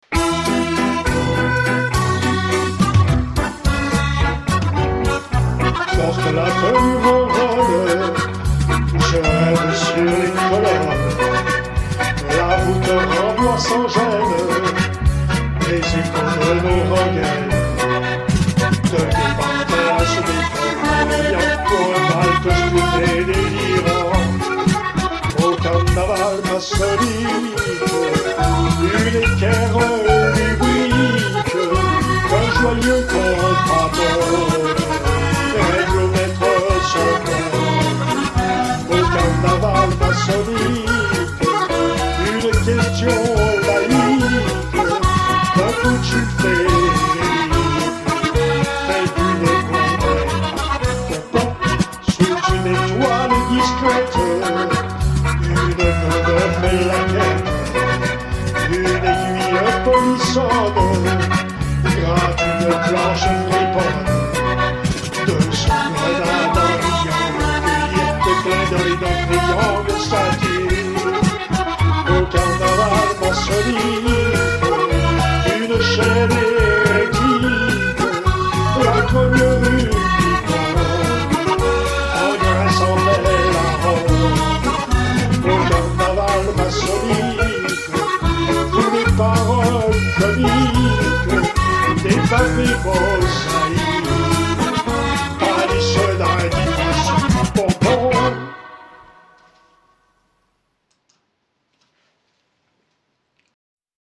Sur l’air de valse